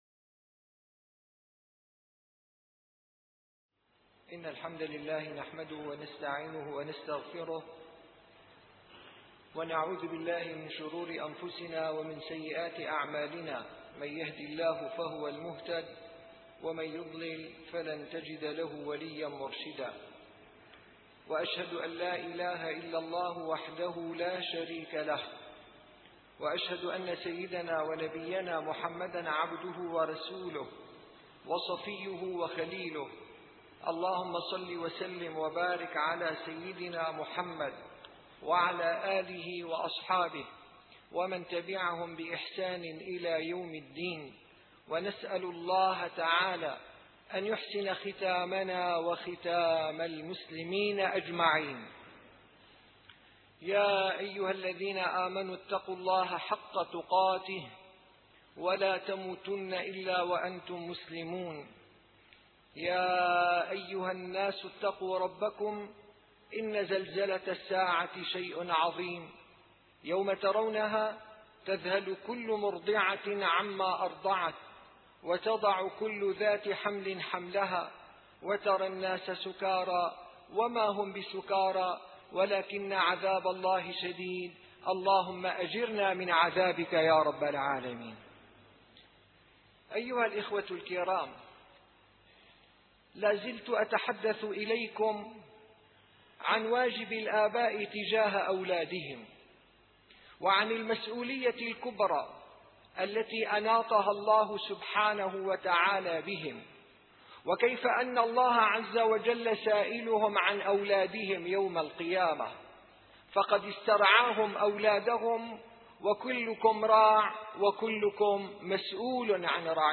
- الخطب - مسؤولية الآباء تجاه الأبناء - 4